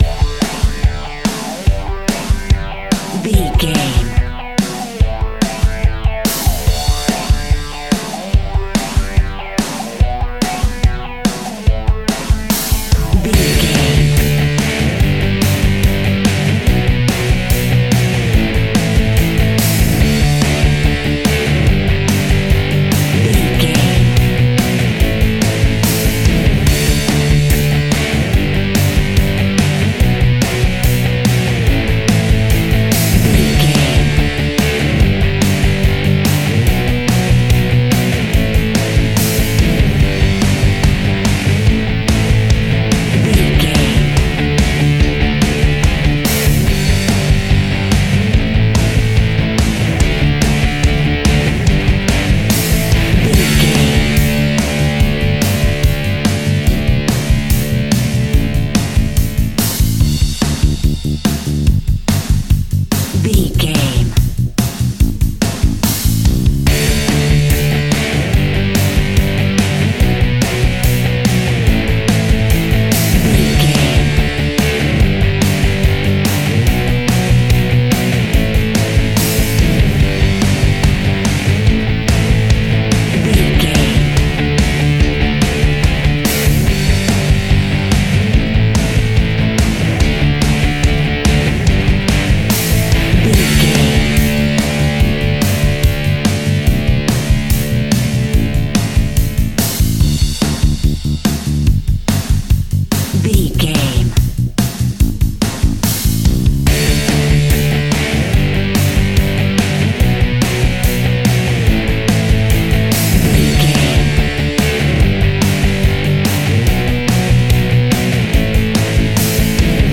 Epic / Action
Fast paced
Ionian/Major
D
hard rock
distortion
punk metal
instrumentals
Rock Bass
heavy drums
distorted guitars
hammond organ